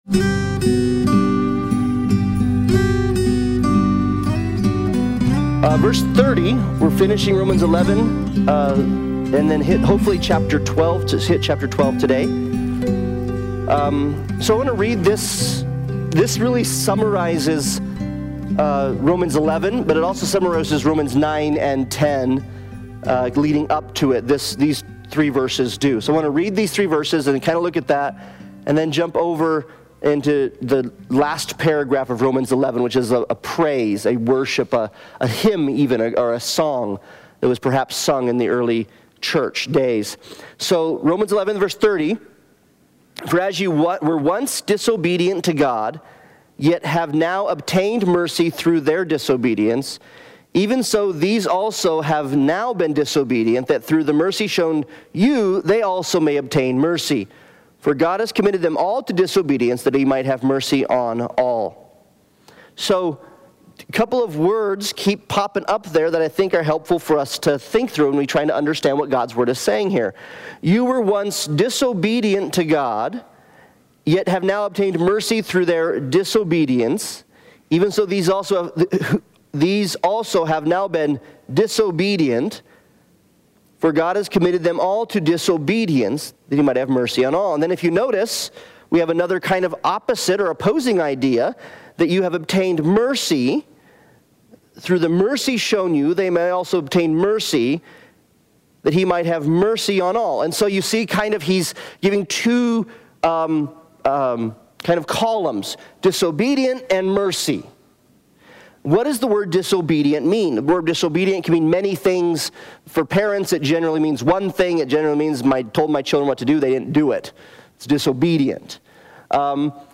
Romans Analysis Passage: Romans 11:30-36 Service Type: Sunday Bible Study « Priest Forever